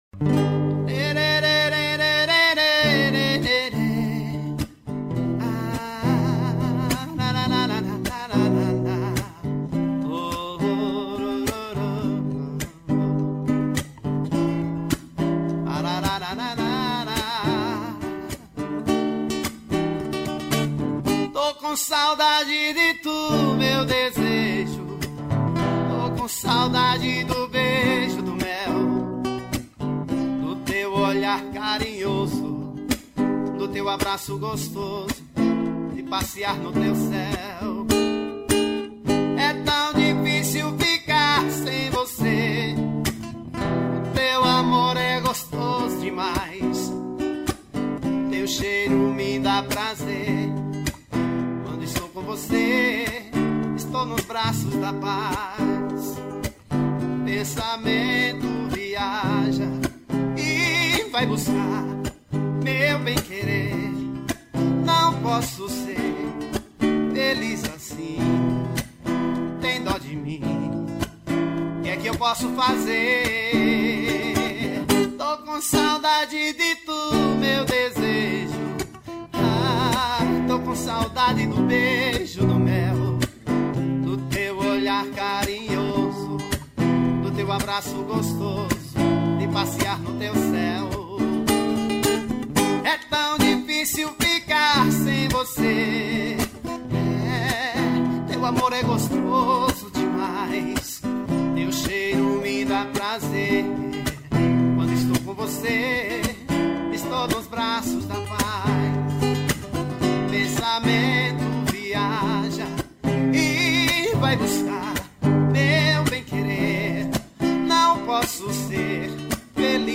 ao vivo voz e violão